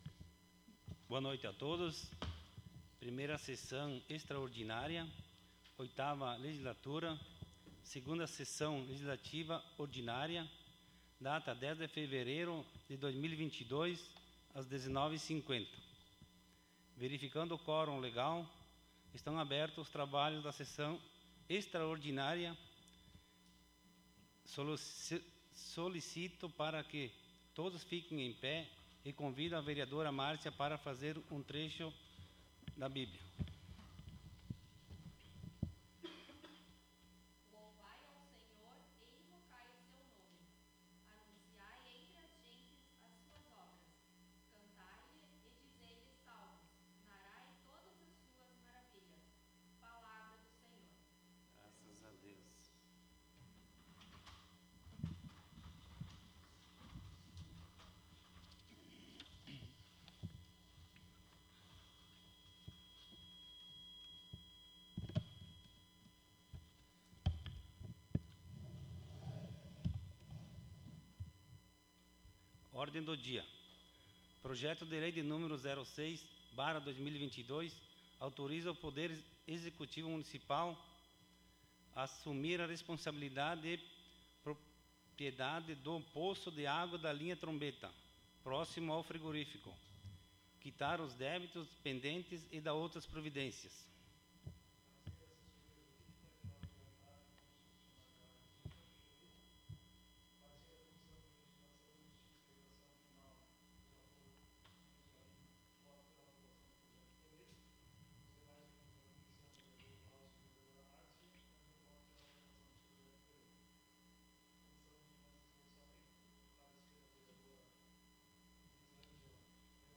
Audios das Sessões